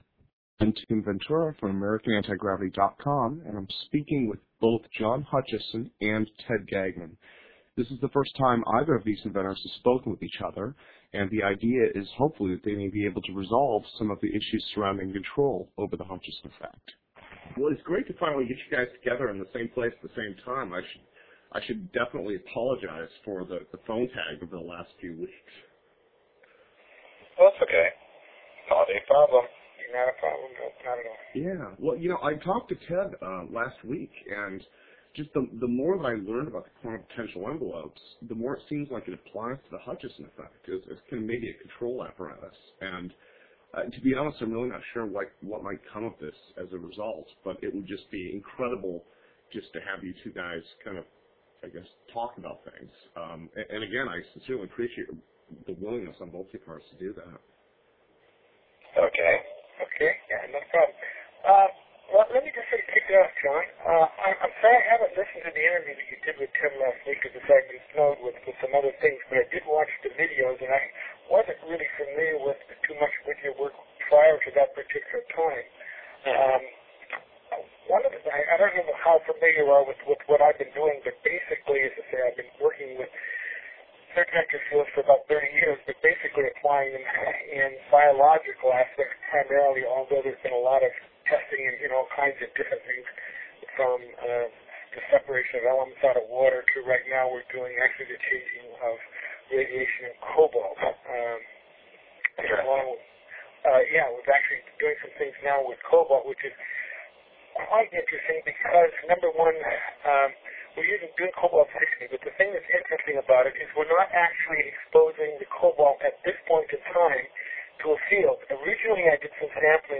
radio interviews and Podcasts